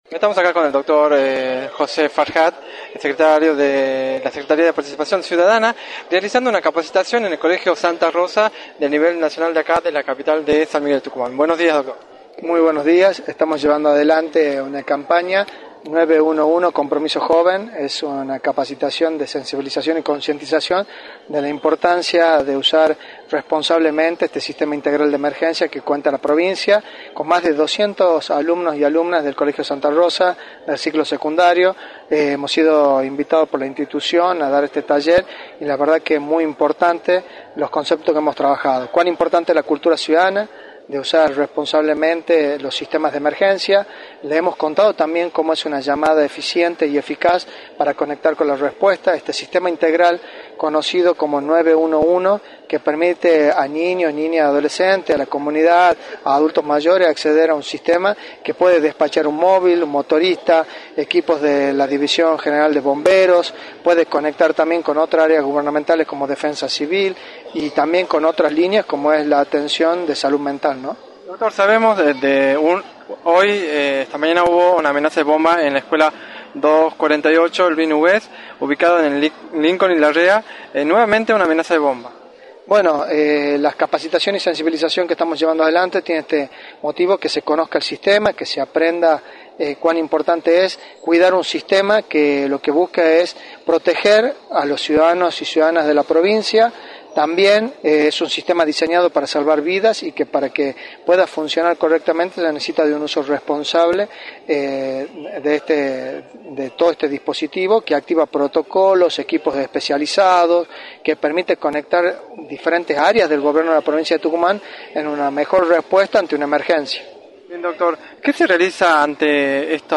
“Les hemos contado cómo debe ser una llama eficiente y eficaz, las capacitaciones de sensibilización tienen el sentido de que se aprenda cuán importante es cuidar un sistema que busca proteger a los ciudadanos de la provincia y que necesita de un uso responsable” señaló José Farhat en entrevista para “La Mañana del Plata”, por la 93.9.